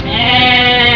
mærkværdige lyde.
Baaaa.WAV